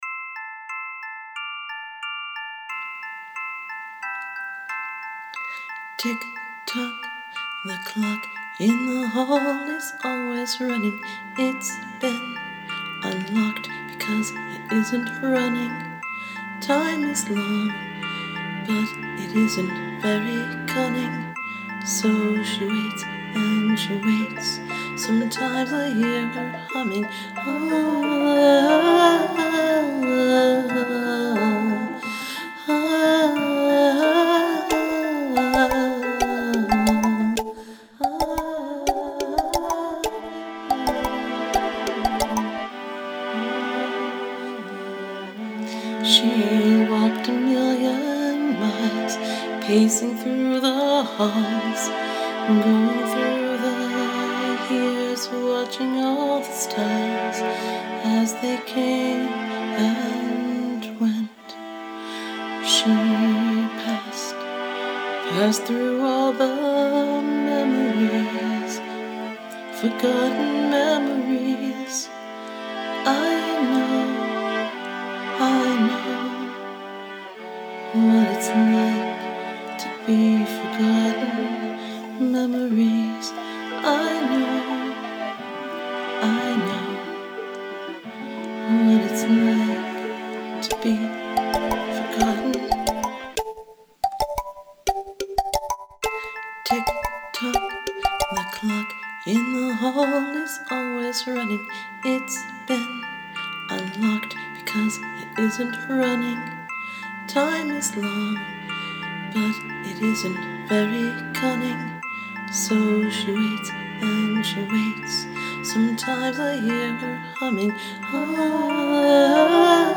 It’s made with loops and snippets from the Garageband library and a handful of effects.